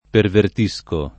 vai all'elenco alfabetico delle voci ingrandisci il carattere 100% rimpicciolisci il carattere stampa invia tramite posta elettronica codividi su Facebook pervertire v.; perverto [ perv $ rto ] (meno com. pervertisco [ pervert &S ko ], ‑sci )